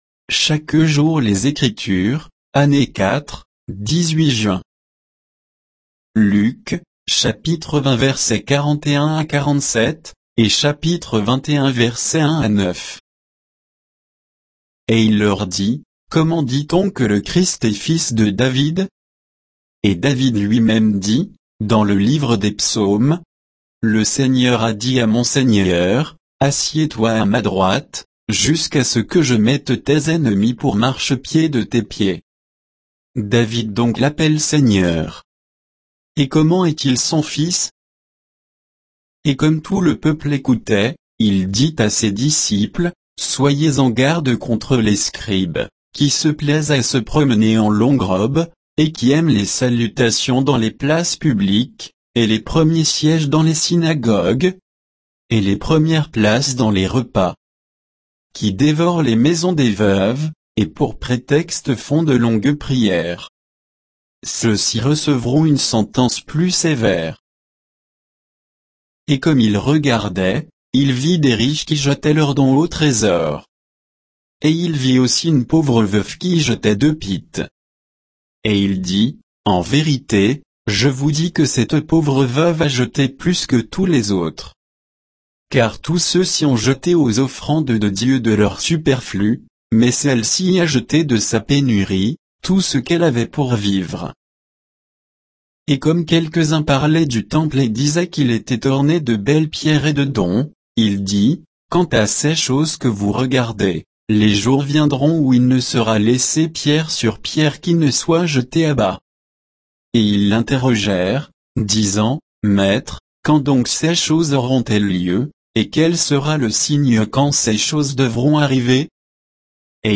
Méditation quoditienne de Chaque jour les Écritures sur Luc 20